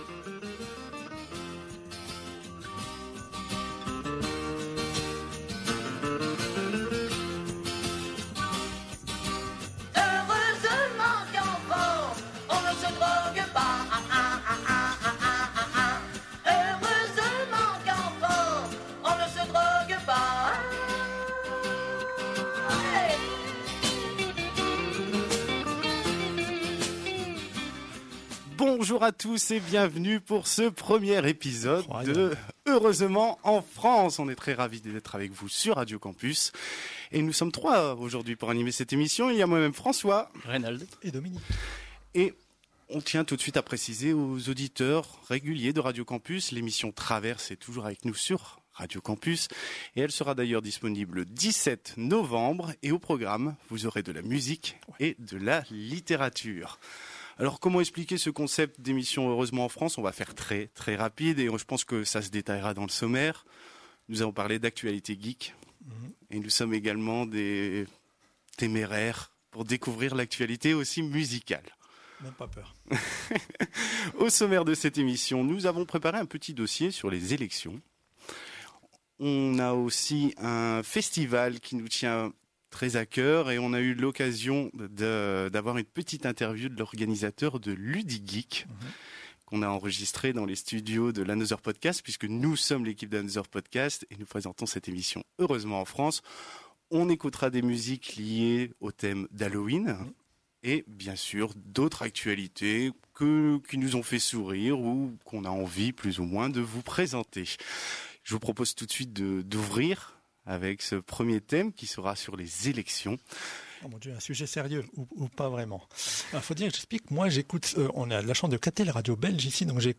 Au sommaire de cet épisode sur RadioCampus :